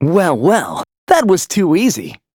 Kibera-Vox_Victory_c.wav